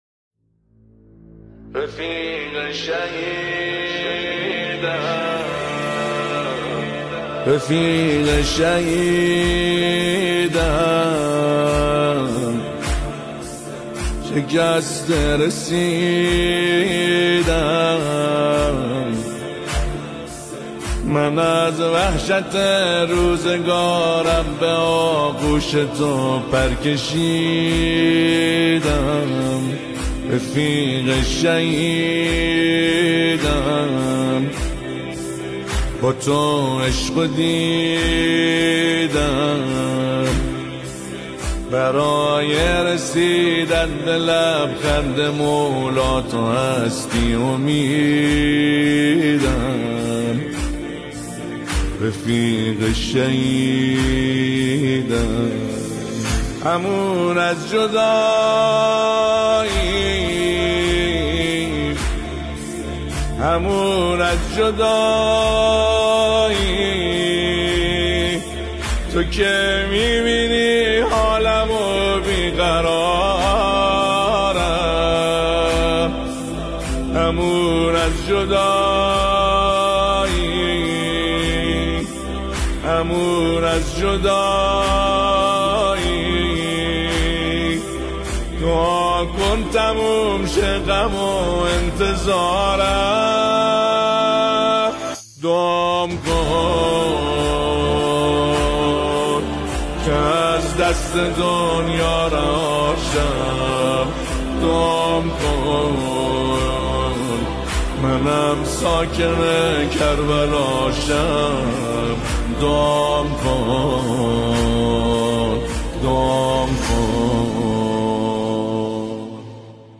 بسیار زیبا و حماسی